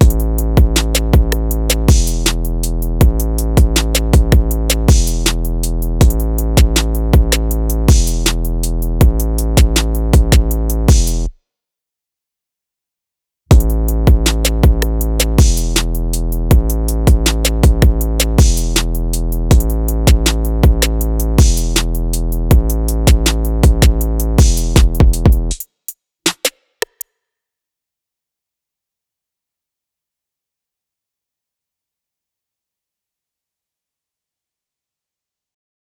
Mezcla Y Mastering
Asi que hoy hice mi practica habitual sobre unos drums de trap con el objetivo de conseguir algo dinámico pero con un Boom en el 808 que impacte y aquí les dejo el resultado, espero sea de vuestro agrado. y que resuelva alguna de vuestras dudas de como conseguir este sonido. y si tienen alguna duda, ya saben, directamente al Whatsapp.
Con-mezcla-y-mastering.wav